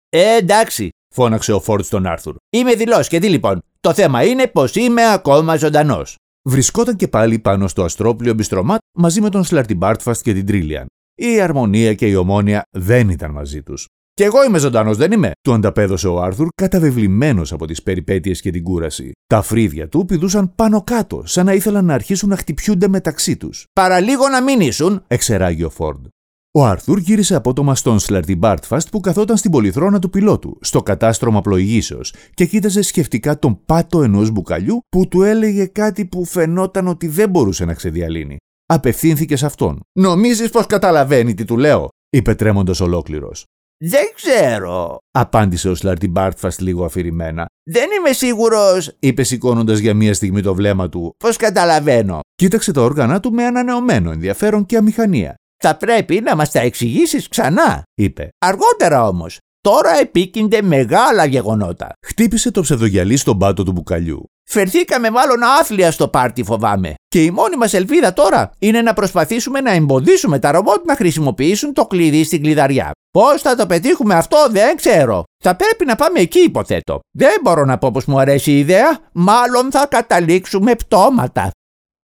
Greek Voice Over artist, radio presenter, TV production and copywriter
Sprechprobe: Sonstiges (Muttersprache):
AKG c214 M-Audio sound card